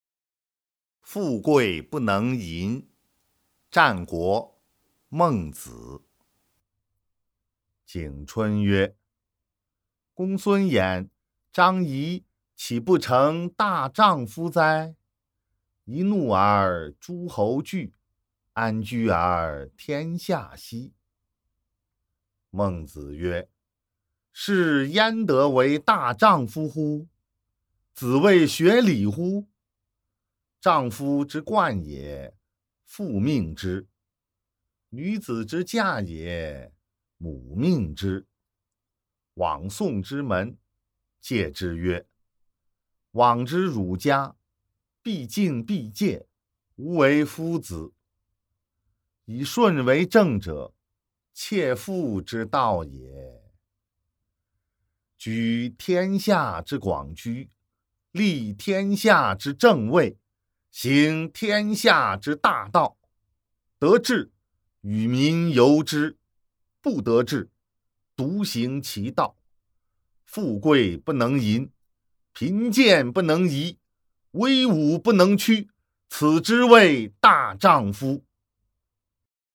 《孟子》富贵不能淫（读诵）